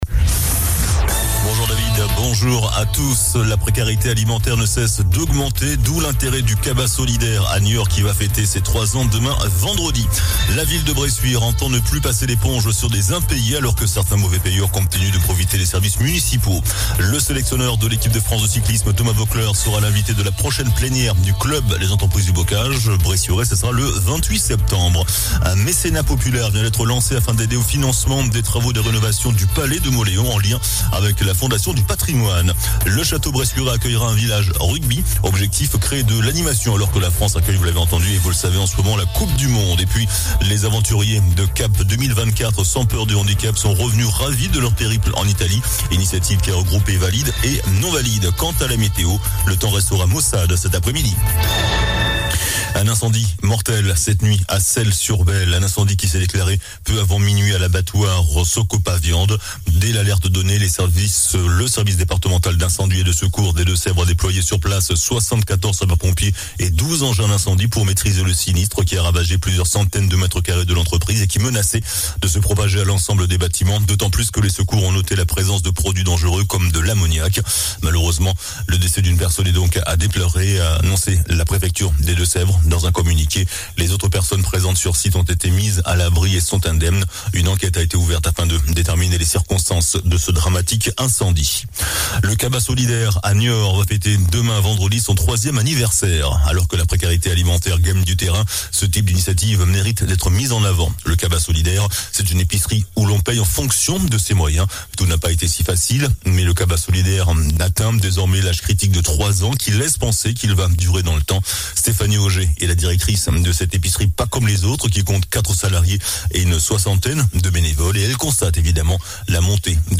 JOURNAL DU JEUDI 21 SEPTEMBRE ( MIDI )